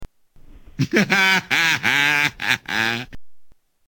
Michael's laugh